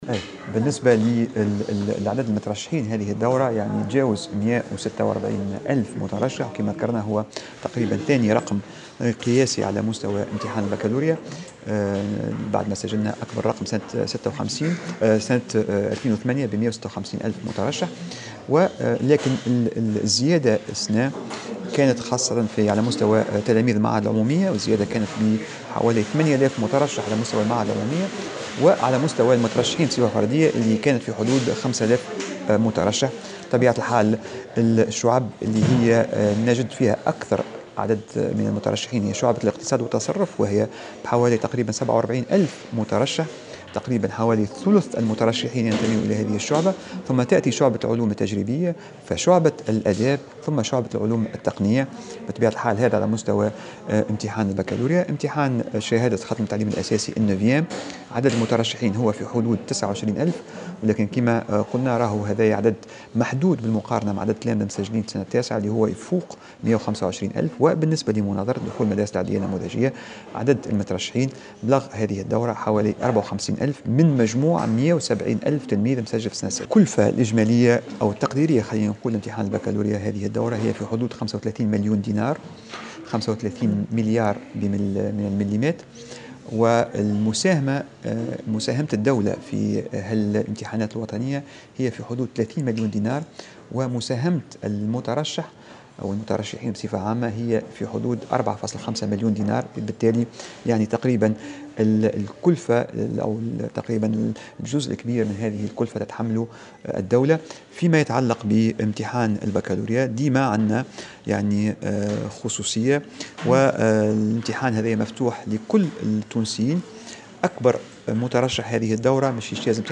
وأضاف على هامش ندوة صحفية عقدتها الوزارة، اليوم الاثنين، أن هذا الرقم يُعتبر ثاني رقم قياسي منذ تاريخ إحداث البكالوريا.